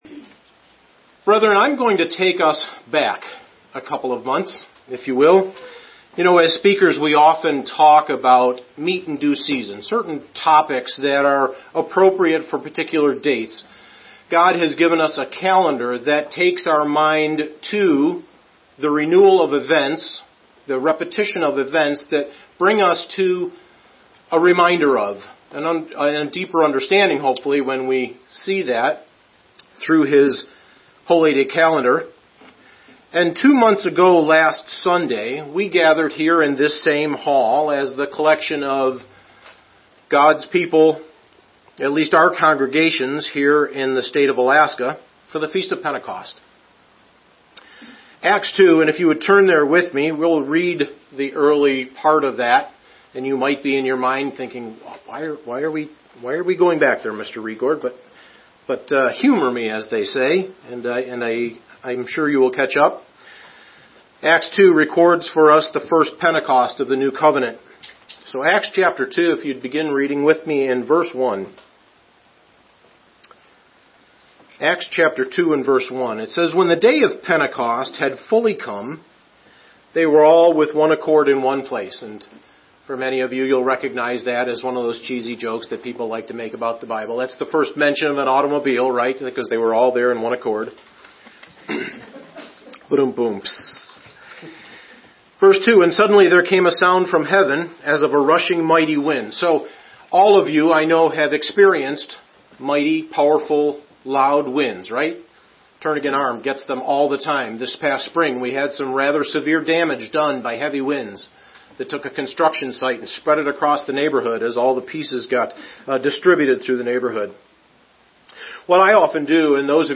Sermons
Given in Anchorage, AK Soldotna, AK